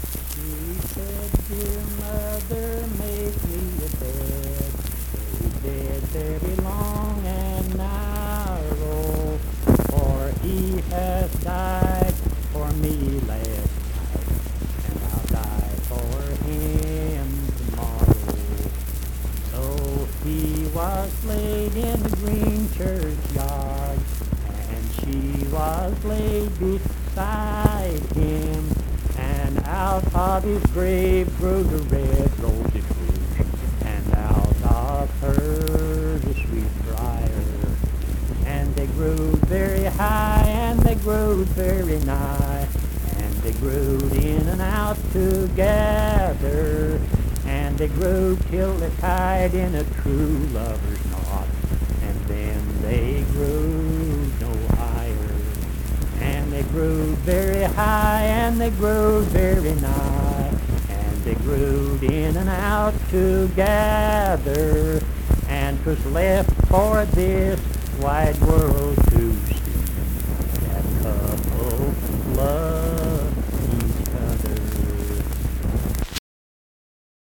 Accompanied (guitar) and unaccompanied vocal music
Performed in Mount Harmony, Marion County, WV.
Voice (sung)